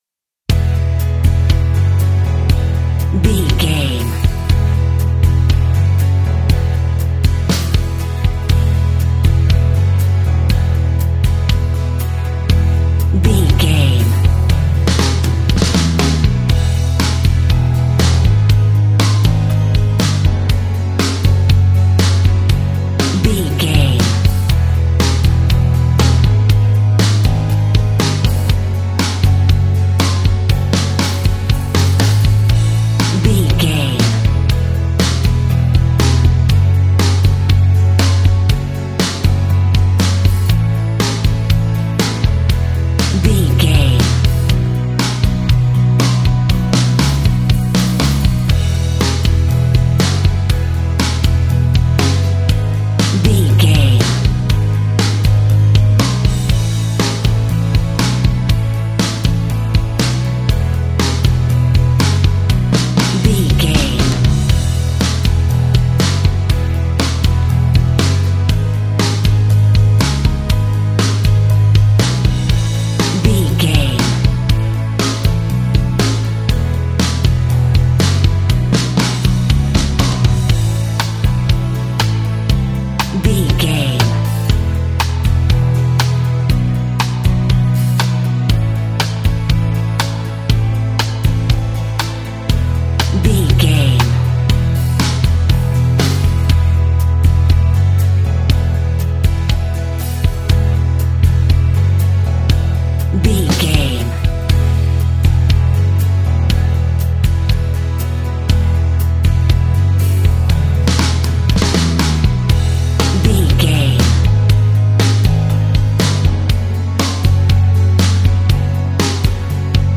Uplifting
Ionian/Major
pop rock
fun
energetic
cheesy
guitars
bass
drums
organ